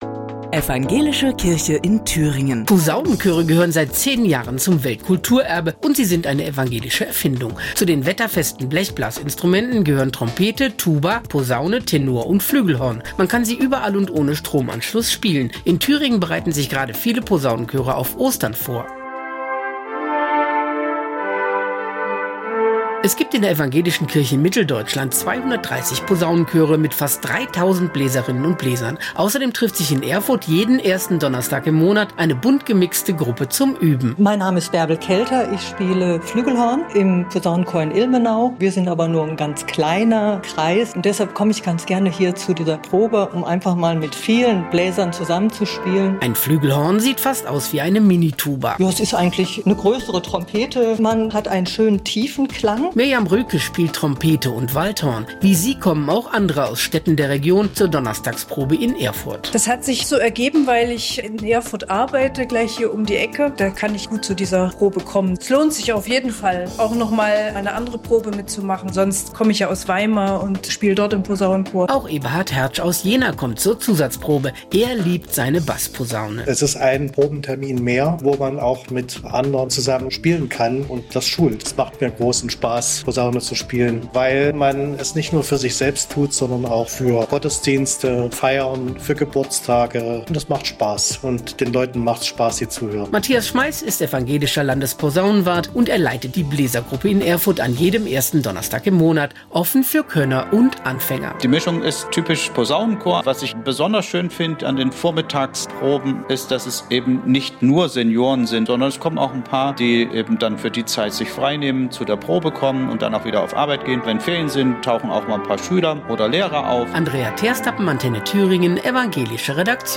iad-antenne-thueringen-posaunenchoere-proben-fuer-ostern-45382.mp3